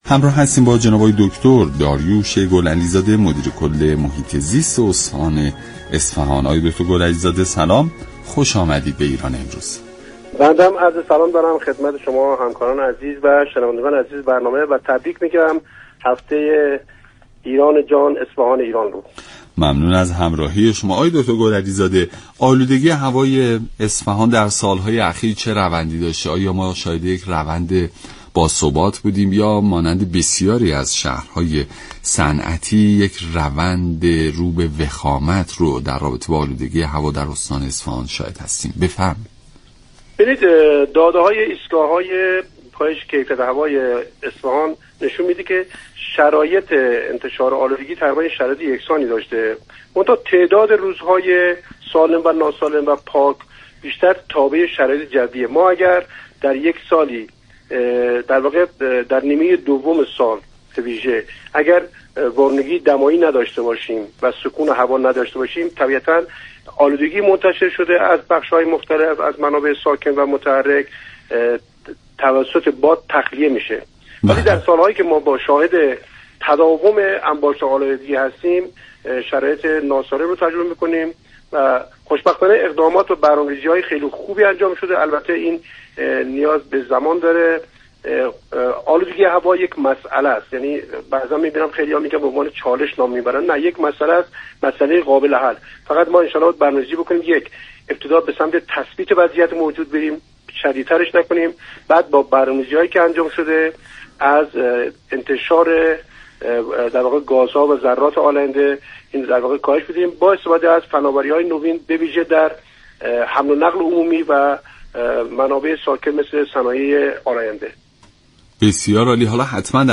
داریوش گل‌علی زاده مدیر كل محیط‌زیست استان اصفهان در برنامه ایران امروز گفت: آلودگی هوا چالش نیست، با برنامه‌ریزی درست این مسئله‌ای قابل حل می شود.